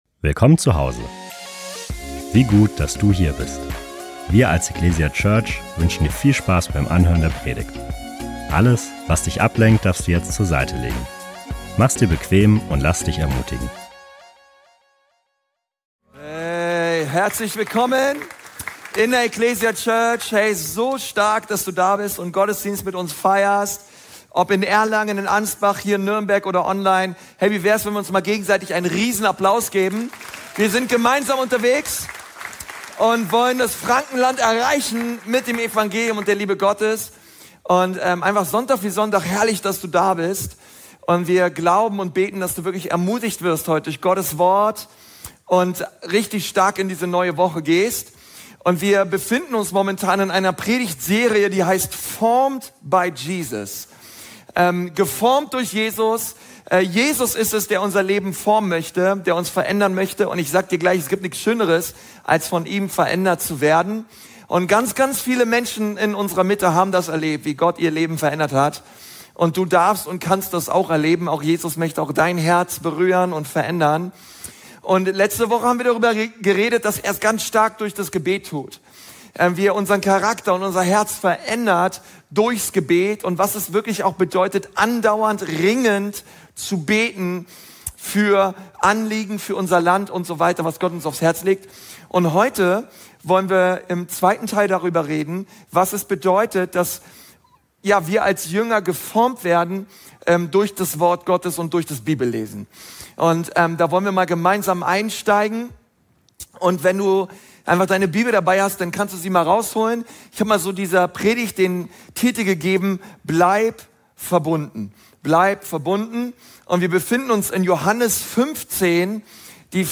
Die Bibelstellen zur Predigt und eine Möglichkeit aktiv mitzuschreiben, findest du in der digitalen Predigtmitschrift.